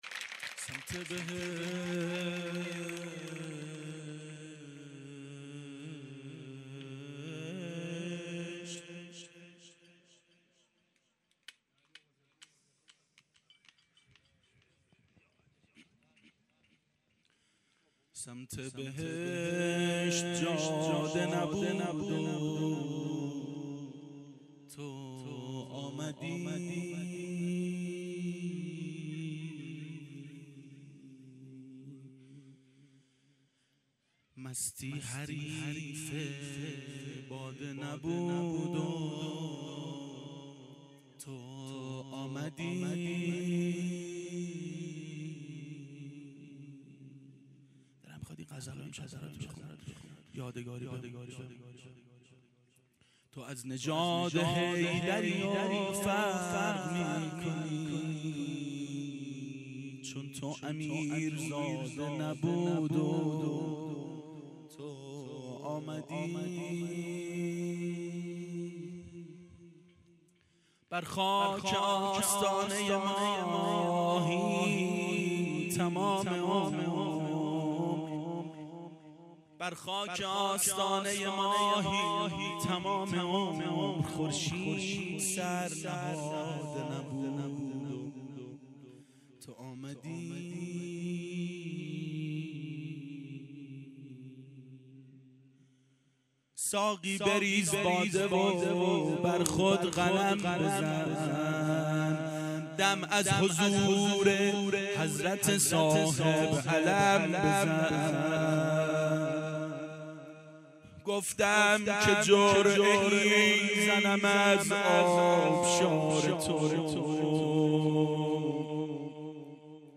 • میلاد امام حسین و حضرت اباالفضل علیهماالسلام 1389 هیئت عاشقان اباالفضل علیه السلام